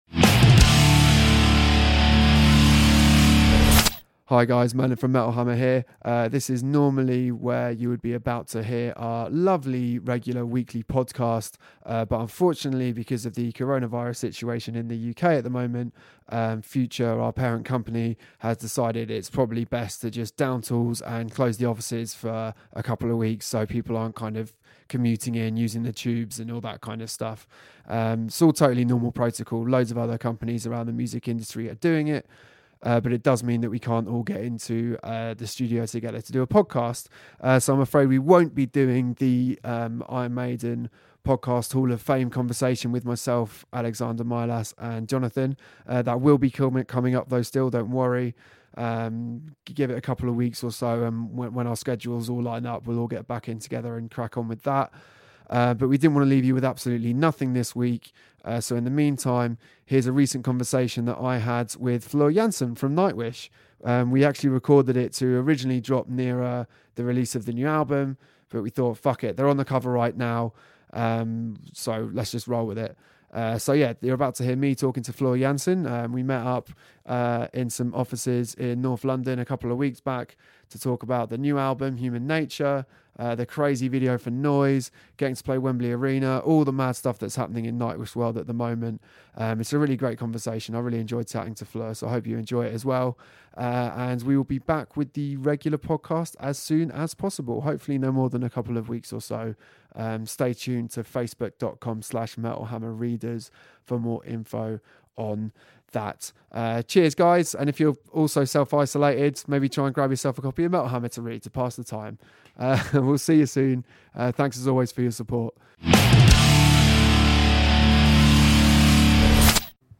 The regular podcast is taking a brief break while we work out a few things with the ongoing coronavirus situation, so in the meantime here's a bonus interview with our current cover star, Nightwish's Floor Jansen!